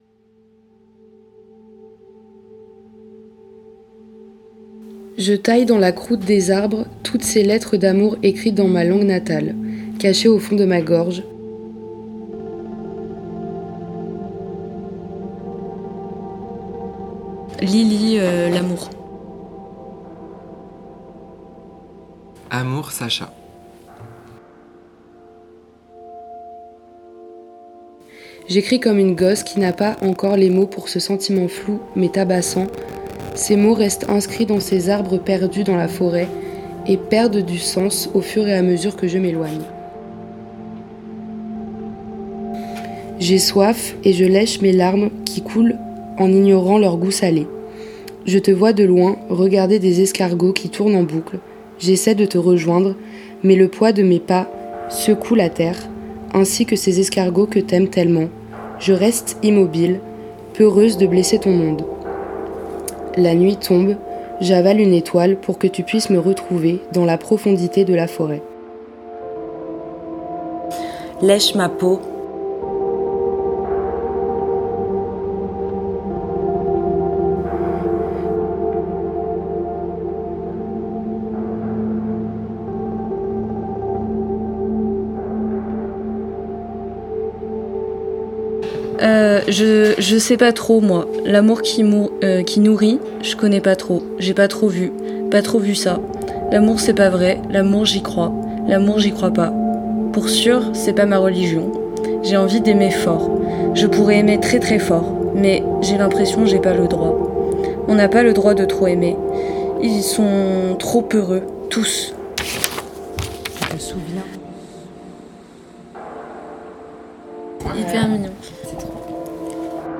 Un documentaire sonore